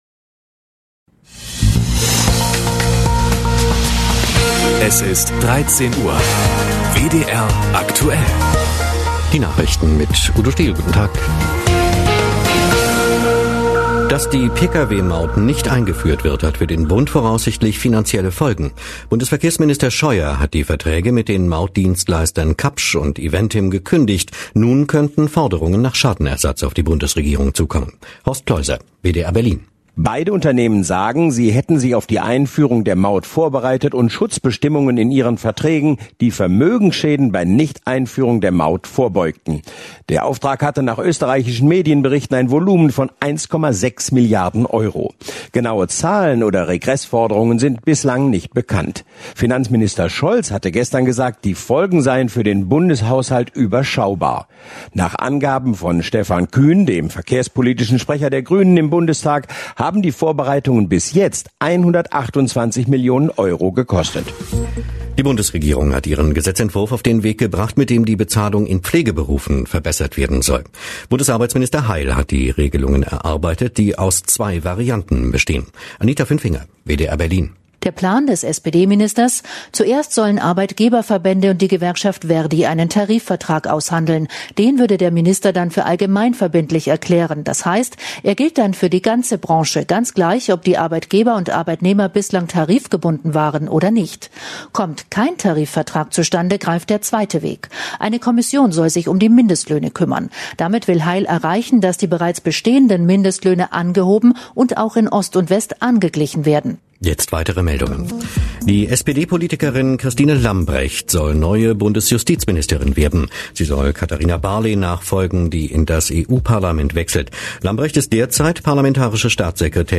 WDR2-Beitrag zu dem Ergebnis des Umwelt- und Planungsausschusses (ab 3:18)